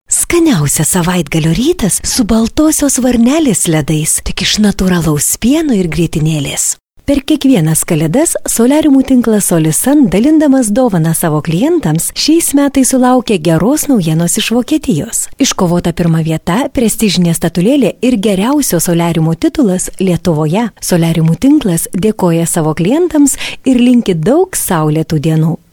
Sprecherin litauisch für TV / Rundfunk / Industrie / Werbung.
Kein Dialekt
Sprechprobe: Industrie (Muttersprache):
Professionell female voice over artist from Lithuania.